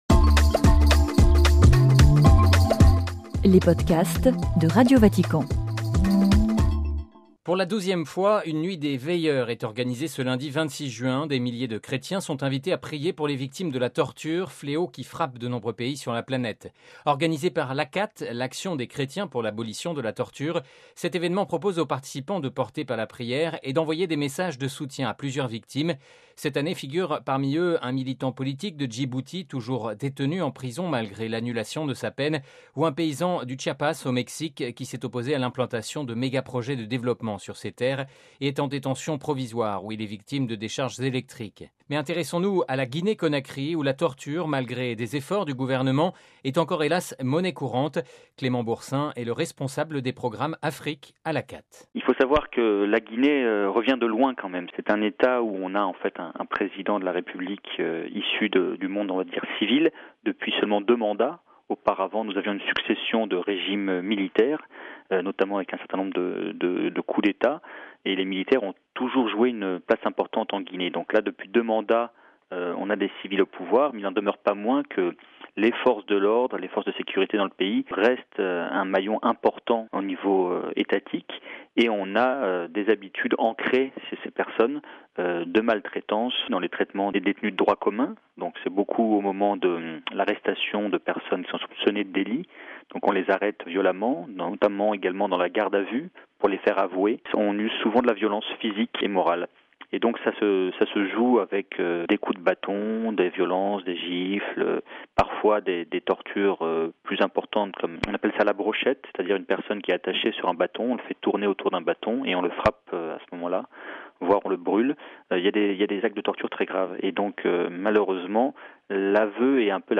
RV) Entretien-